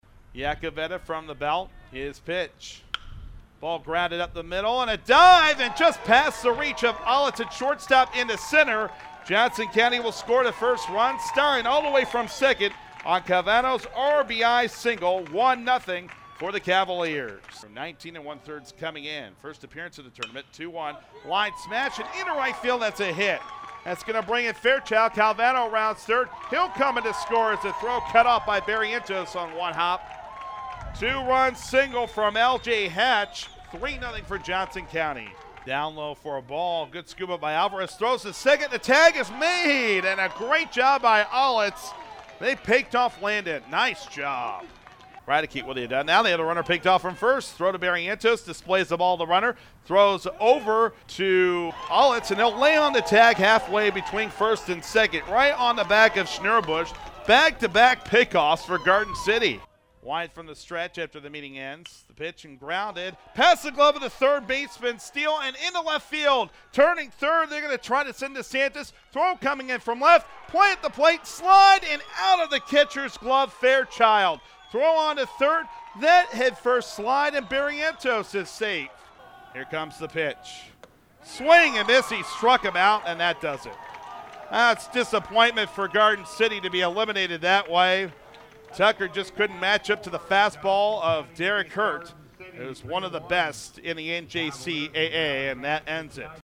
Game Highlights